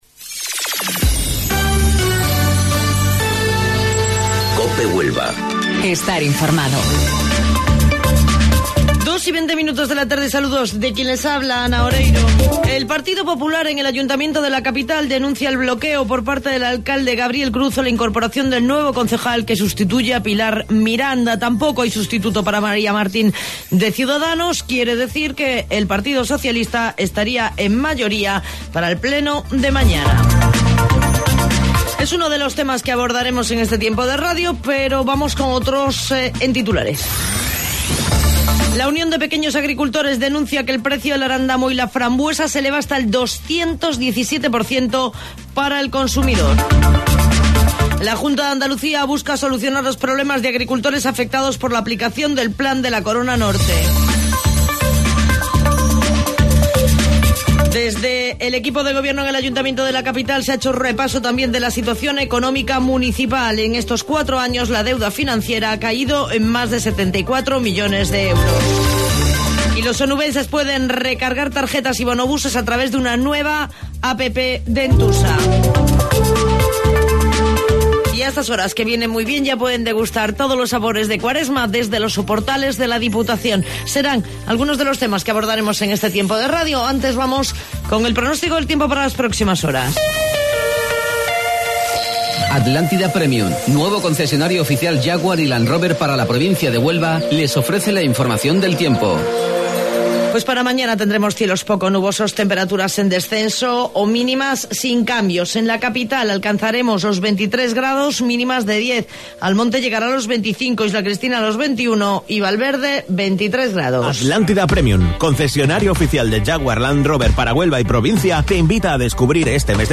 AUDIO: Informativo Local 14:20 del 26 de Marzo